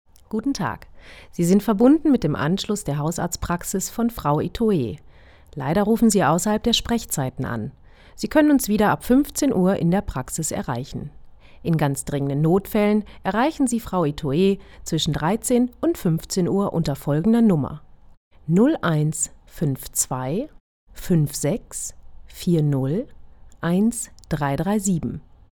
Stimme 96  Englisch - NativeSpeaker
Native Speaker englisch, spricht aber auch fließend deutsch
Voice-Over, Spots. etc.